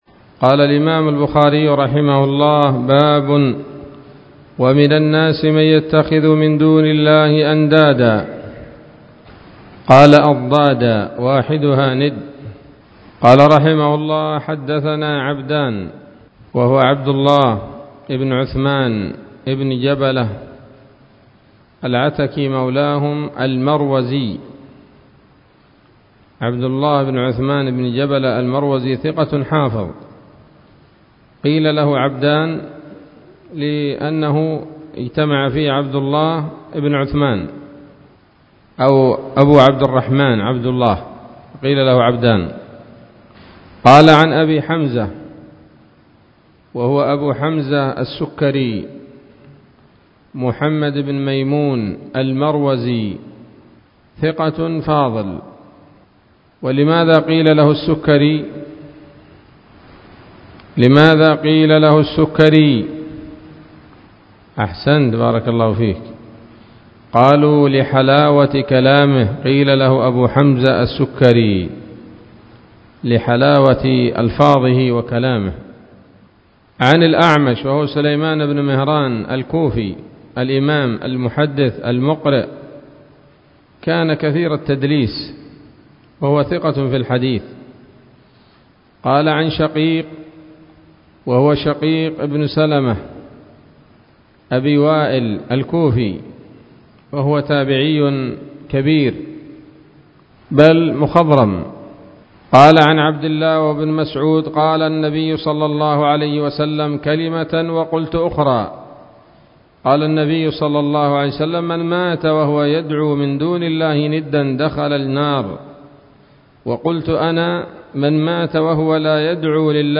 الدرس التاسع عشر من كتاب التفسير من صحيح الإمام البخاري